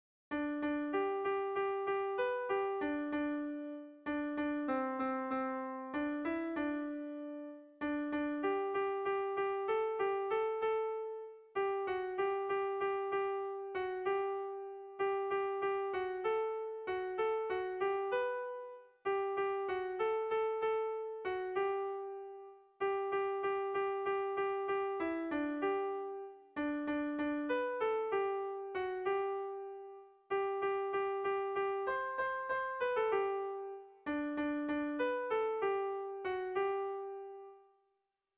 A1A2BDE